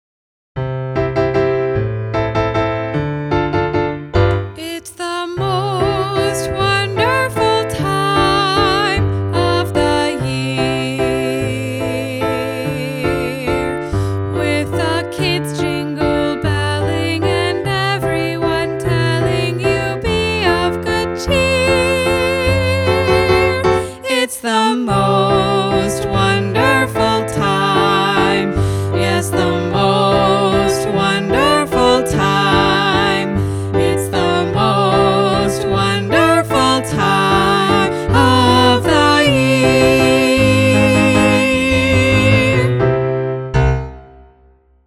Child-Led Advent Worship